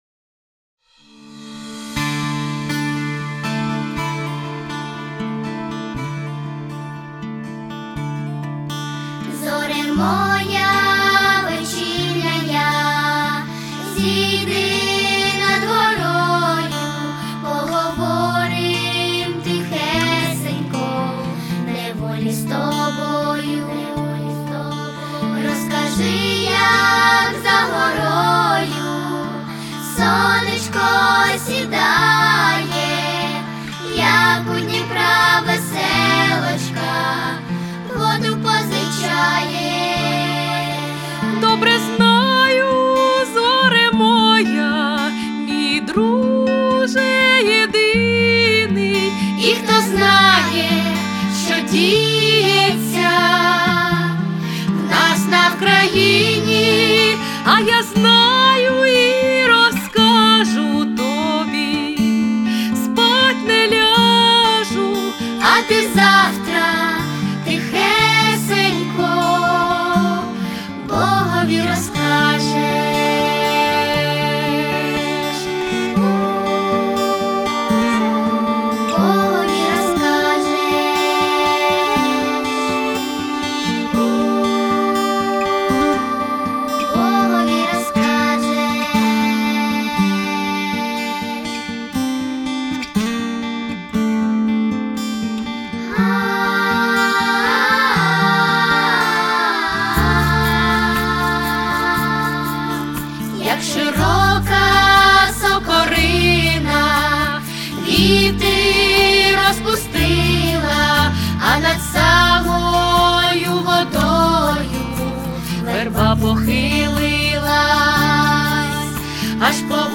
двоголосна партитура з якісним мінусом.
виконує дитячий ансамбль